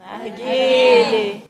Escucha y comparte momento narguile. som bizarro do krai. 52 vistas.